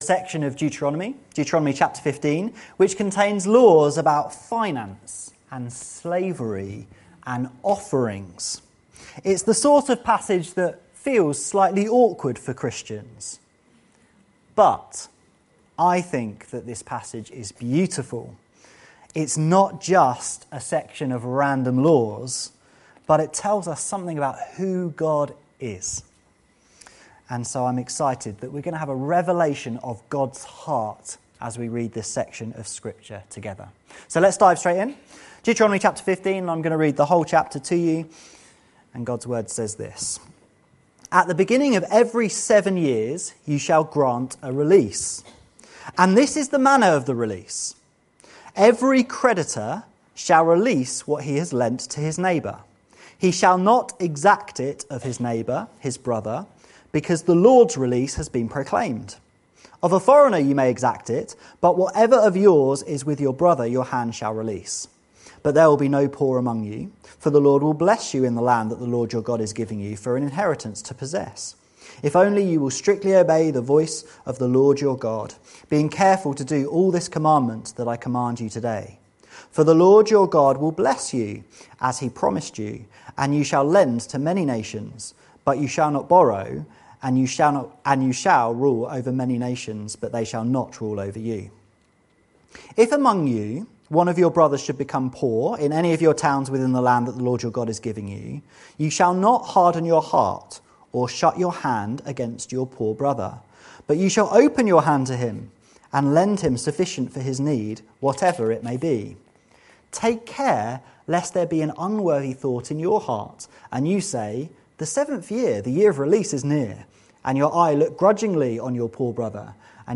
A Sermon from Deuteronomy 15 about who a list of seemingly random laws actually reveals much about who God is.